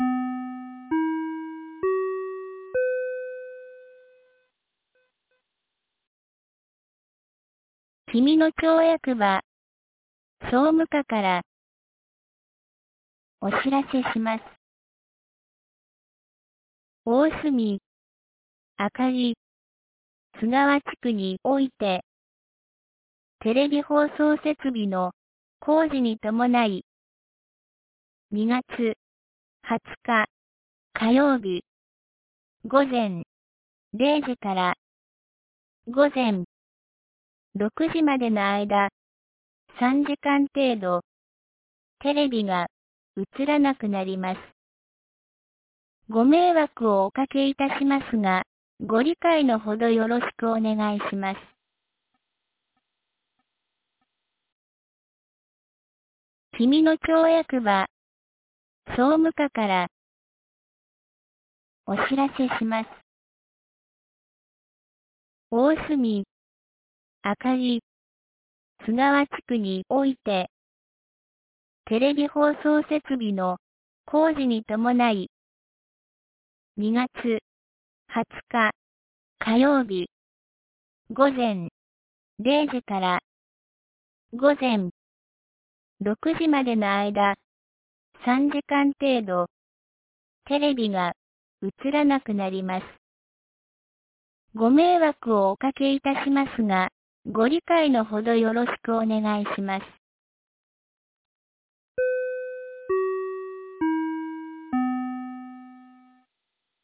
2024年02月17日 12時31分に、紀美野町より上神野地区へ放送がありました。